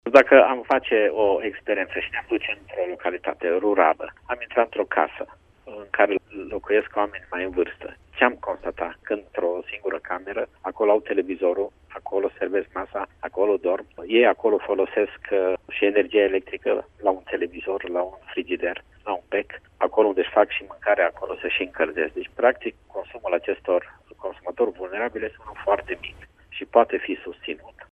Astfel, vor fi acordate ajutoare doar acelor persoane care nu-şi pot achita facturile la energie sau gaze naturale. Secretarul de stat în Ministerul Energiei, Nicolae Havrileţ, spune că autorităţile vor să identifice familiile vulnerabile, cu venituri mici, iar informaţii în acest sens pot fi obţinute şi de la distribuitorii sau furnizorii de electricitate şi gaze, care cunosc cel mai bine situaţia din comunităţi.